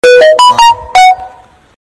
Android Notification Sound Effect Free Download
Android Notification